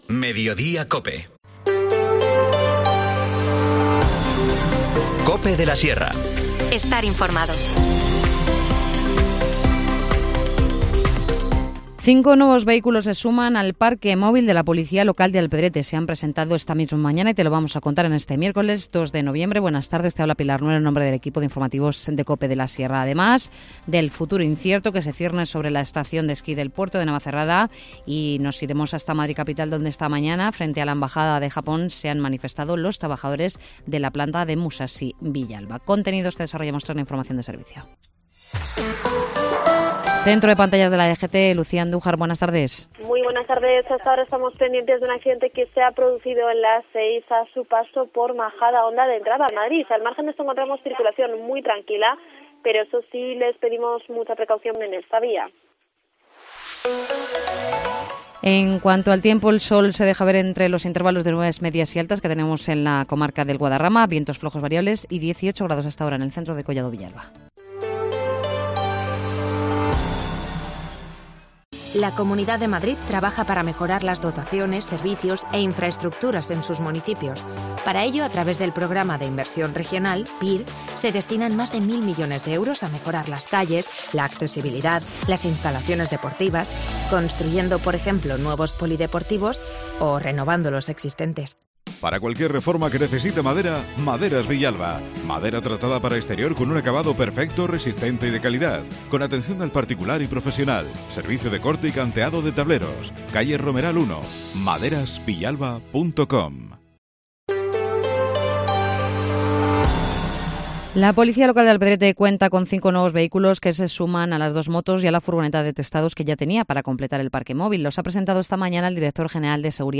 Informativo Mediodía 2 noviembre